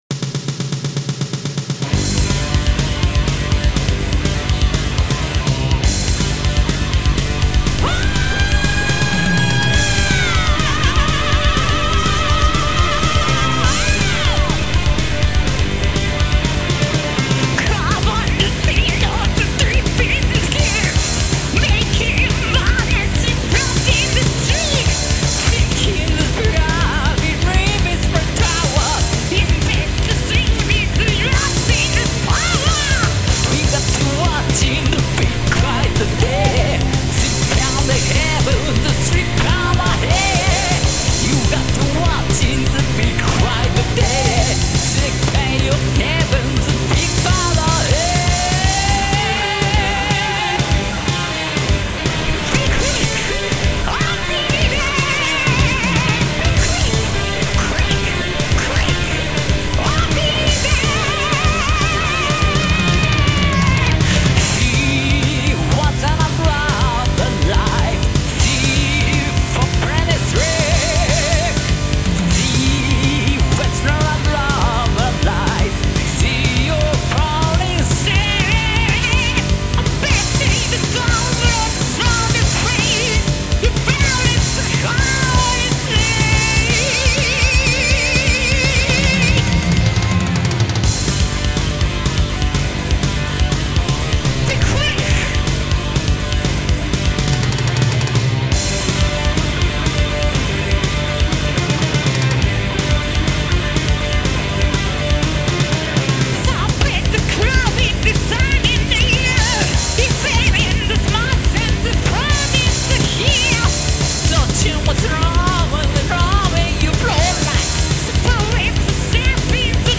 ボーカル
ギター
私は７０年代のボロマイクでレコーディングしています。